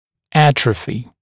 [‘ætrəfɪ][‘этрэфи]атрофия; атрофироваться